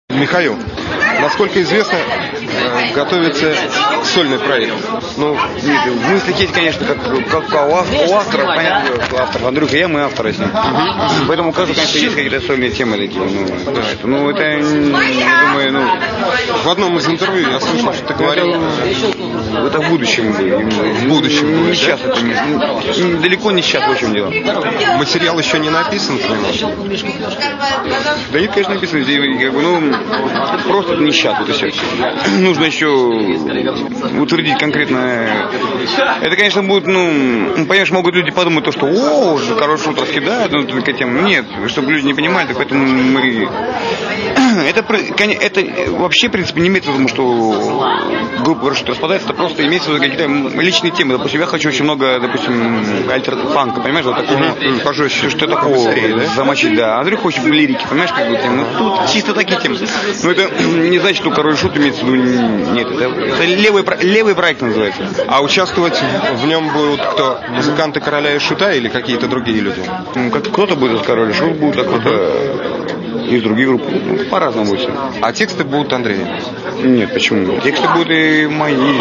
Интервью с Михаилом Горшеневым